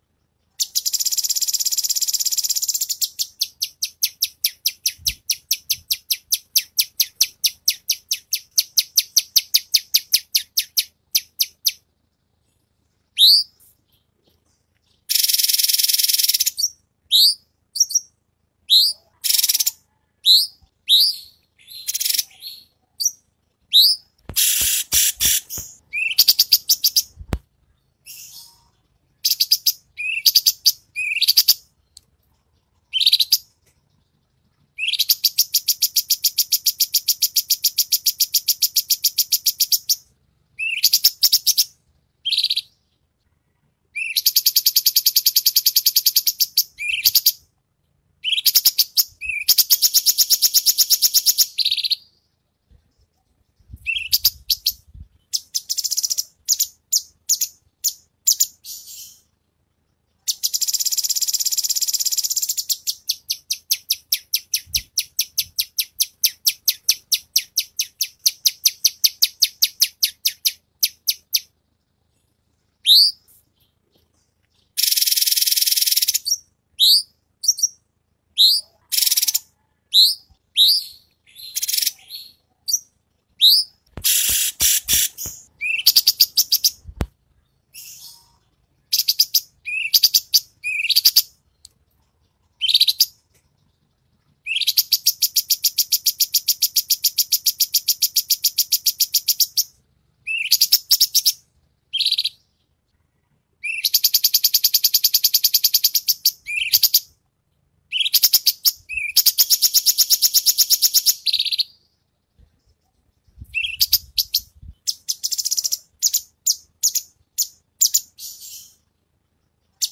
Nikmati suara Cucak Cungkok super gacor dengan ngerol panjang, suara pedas, jernih, dan tembakan istimewa. Cocok untuk masteran atau memancing burung lain agar cepat bunyi.
Suara Burung Cucak Cungkok Ngerol Panjang
Tag: suara burung cucak cungkok suara burung kecil
suara-burung-cucak-cungkok-ngerol-panjang-id-www_tiengdong_com.mp3